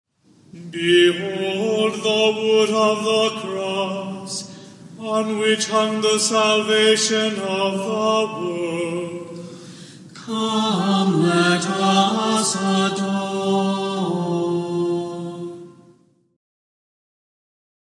Chant for Good Friday Open the score: Showing_Of_The_Holy_Cross (Three options are shown.)